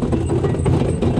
PixelPerfectionCE/assets/minecraft/sounds/minecart/inside.ogg at mc116